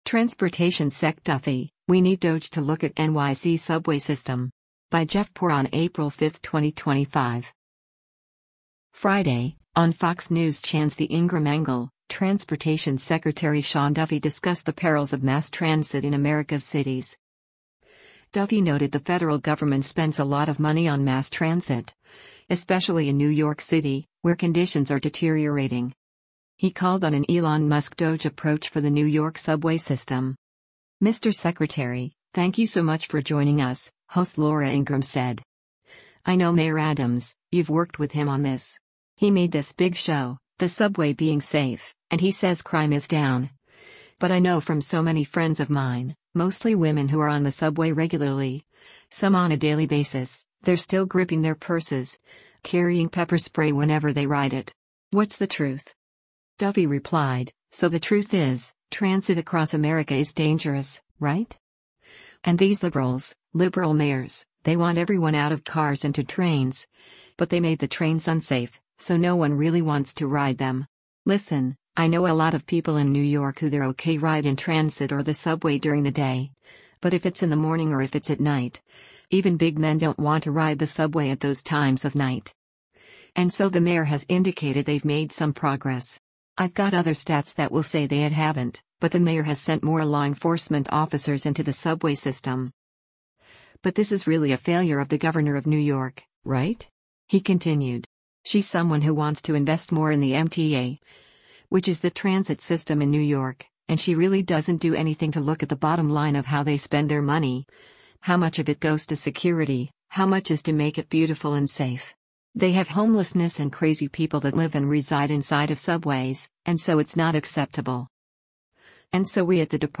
Friday, on Fox News Channe’s “The Ingraham Angle,” Transportation Secretary Sean Duffy discussed the perils of mass transit in America’s cities.